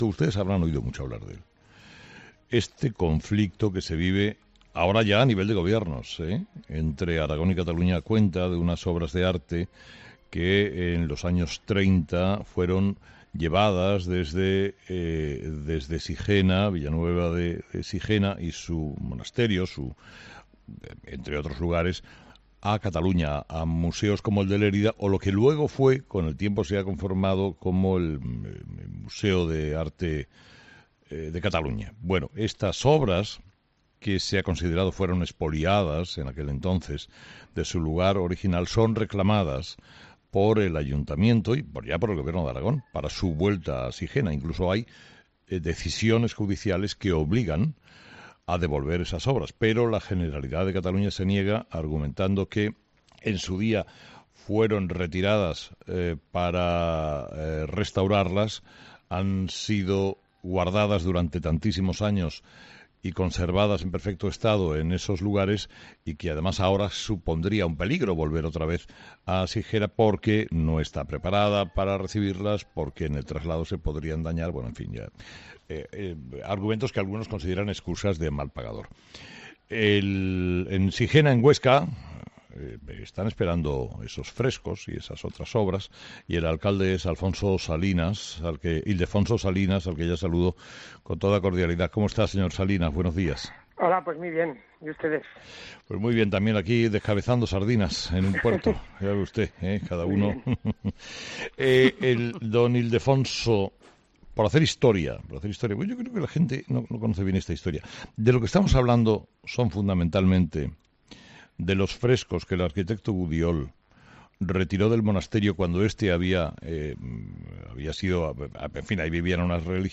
Ildefonso Salillas, alcalde de Villanueva de Sijena